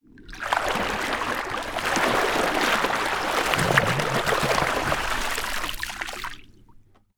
Water_41.wav